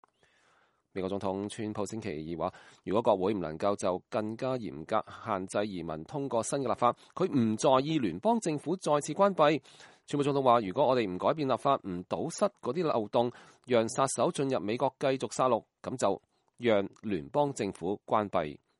川普總統在白宮對討論拉美黑幫團夥MS-13暴力行為的執法人員講話時表達了以上立場。